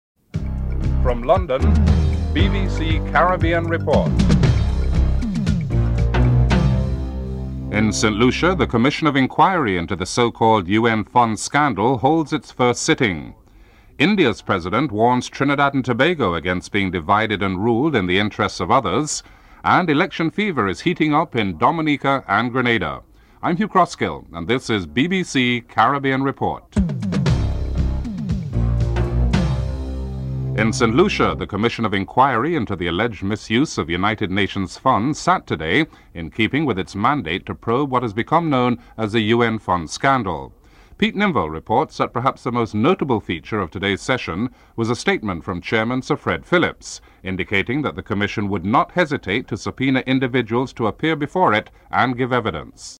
9. Recap of top stories (14:51-15:10)